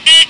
Digital Honk Sound Effect
Download a high-quality digital honk sound effect.
digital-honk.mp3